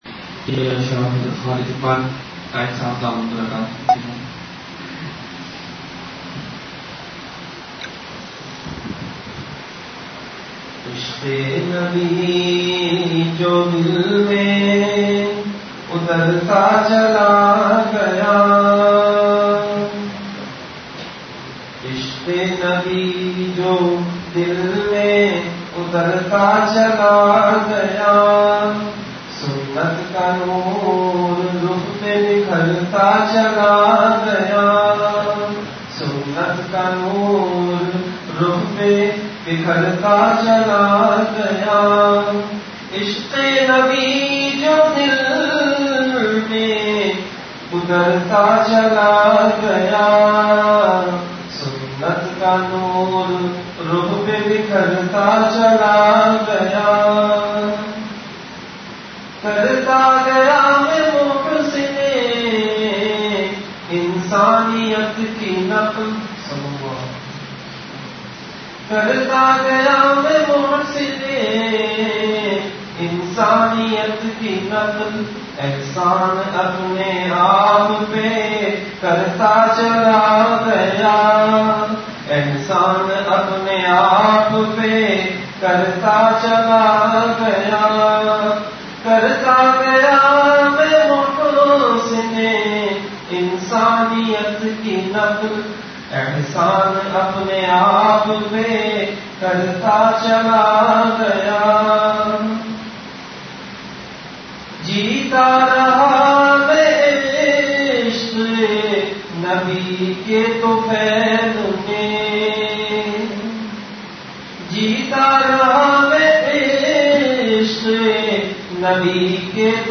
Delivered at Jamia Masjid Bait-ul-Mukkaram, Karachi.
Majlis-e-Zikr · Jamia Masjid Bait-ul-Mukkaram, Karachi